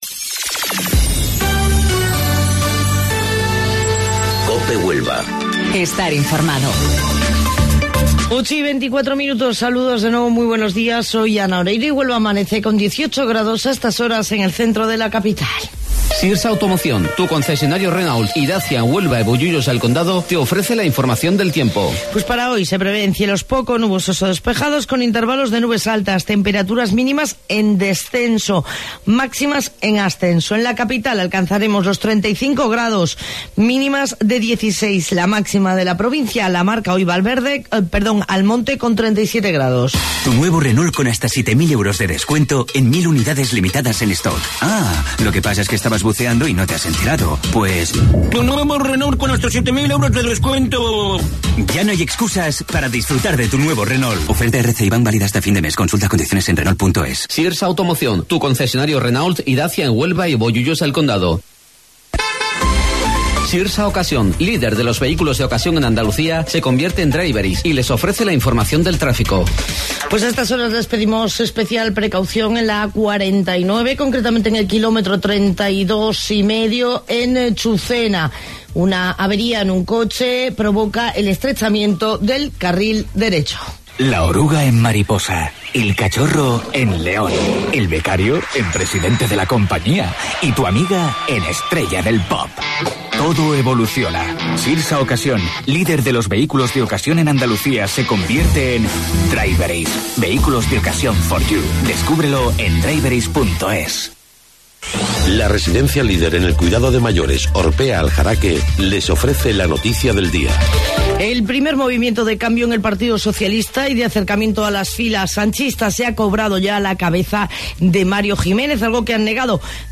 AUDIO: Informativo Local 08:25 del 30 de Julio